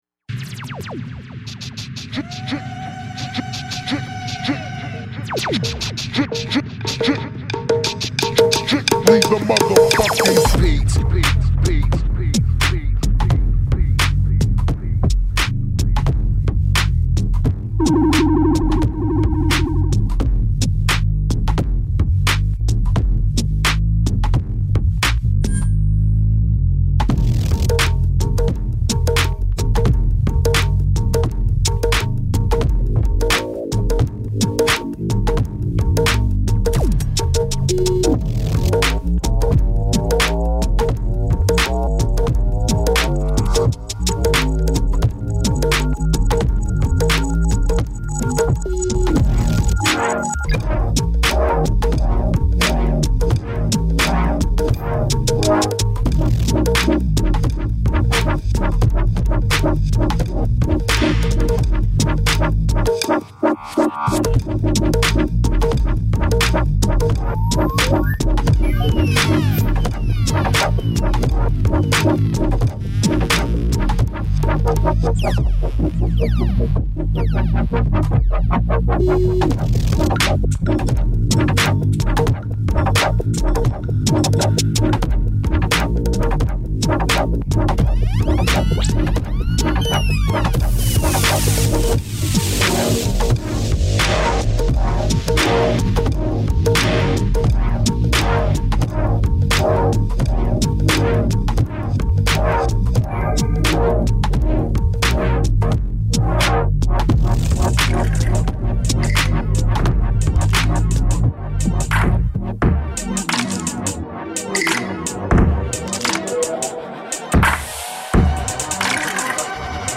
＊試聴はダイジェストです。